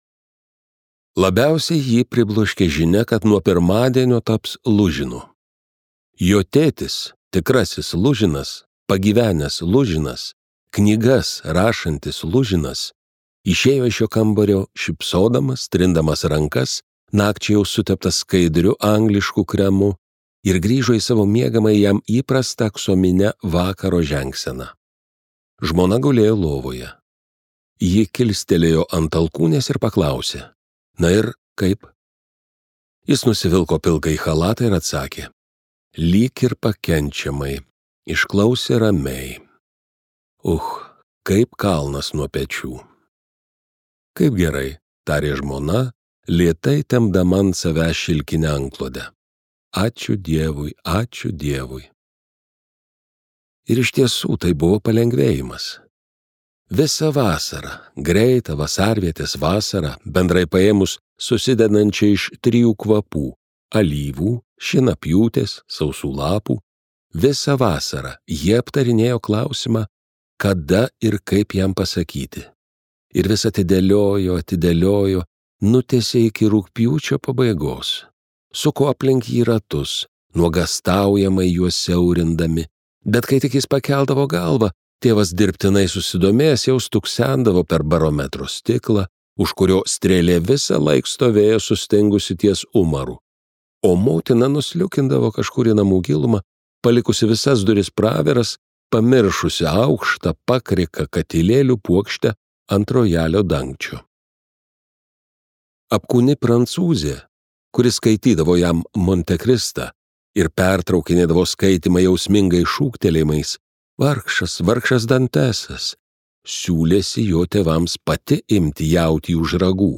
Lužino gynyba | Audioknygos | baltos lankos